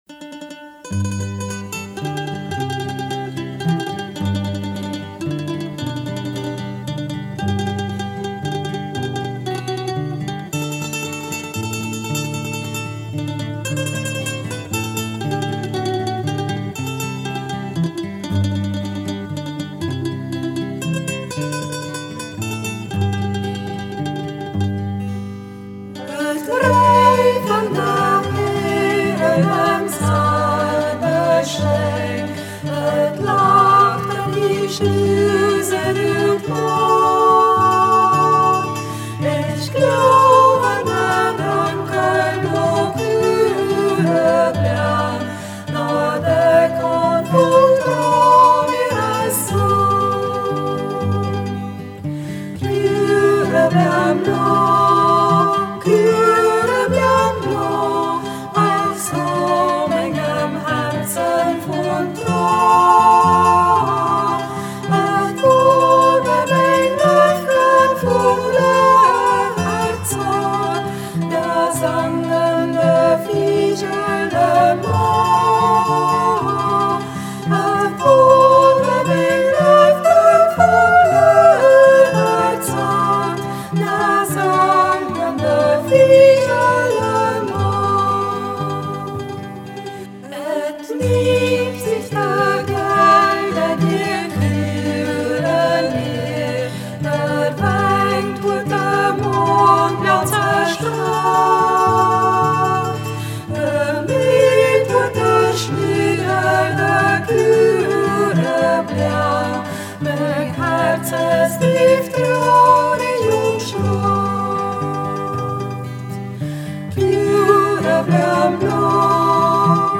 Ortsmundart: Mediasch